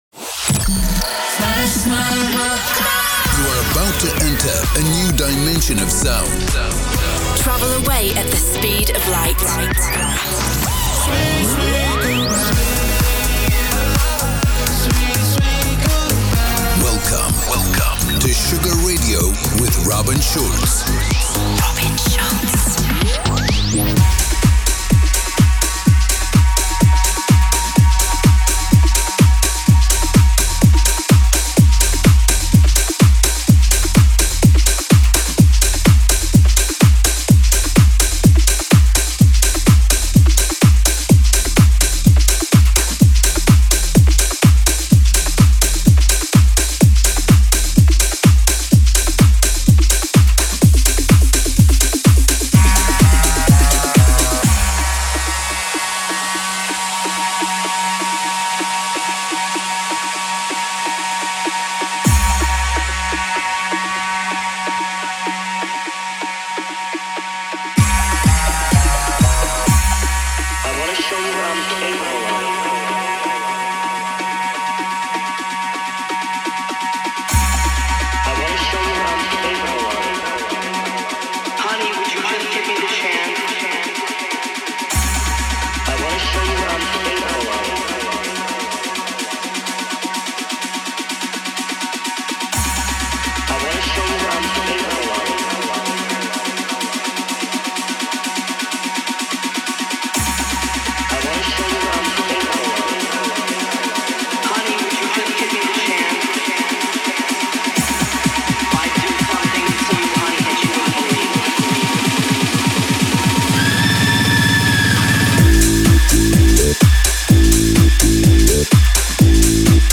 music DJ Mix in MP3 format
Genre: Electro House